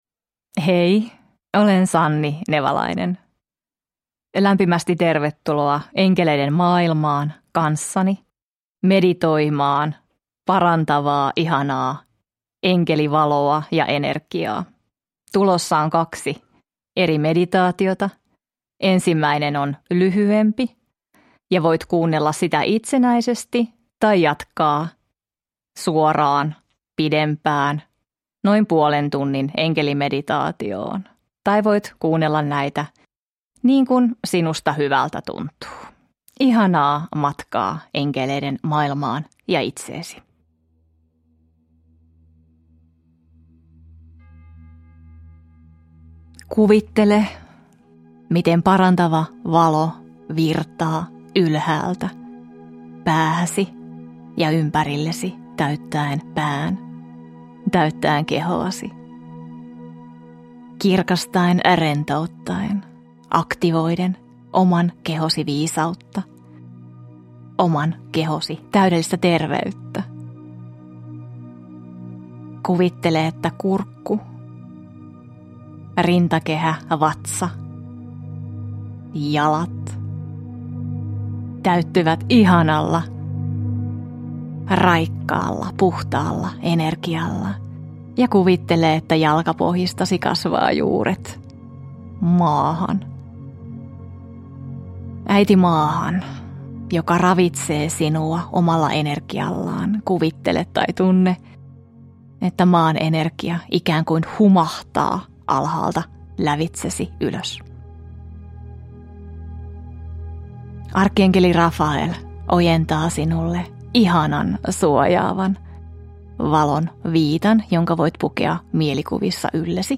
Enkeli meditaatio: Paraneminen – Ljudbok – Laddas ner